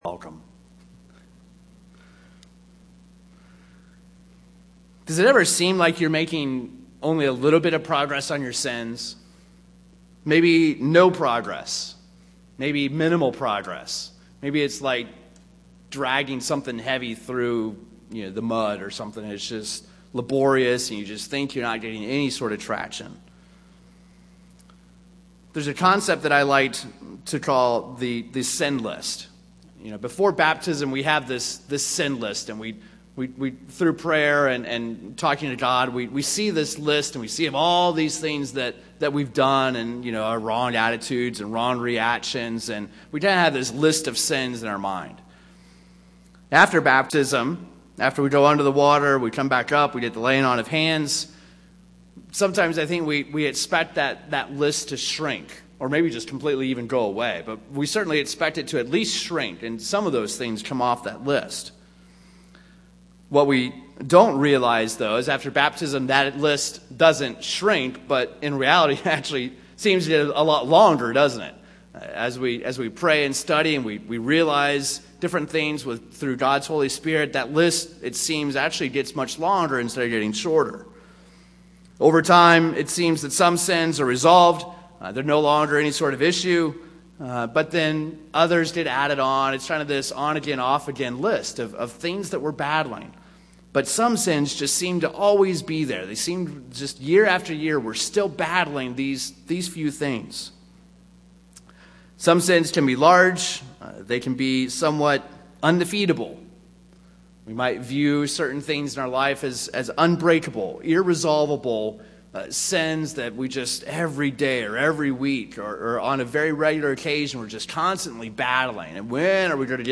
These are the notes taken live during services as captioning for the deaf and hard of hearing.
UCG Sermon Notes These are the notes taken live during services as captioning for the deaf and hard of hearing.